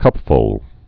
(kŭpfl)